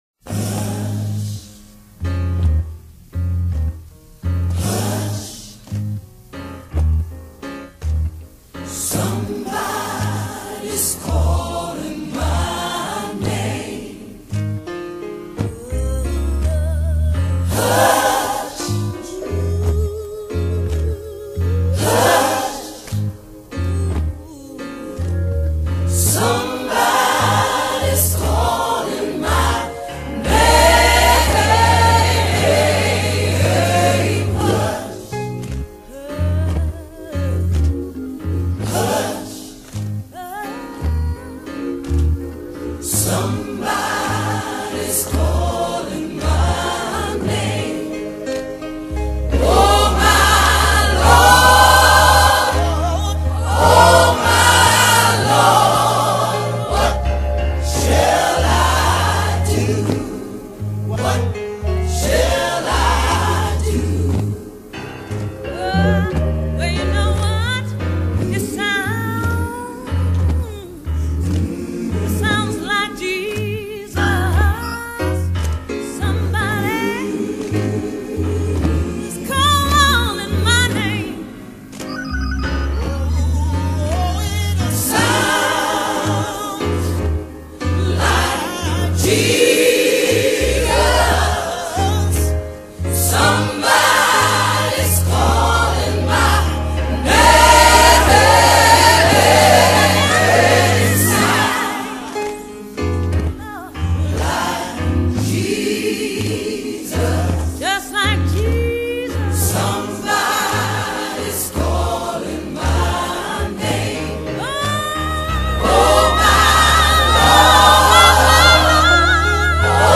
in Ab